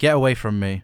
Voice Lines / Dismissive
get away from me.wav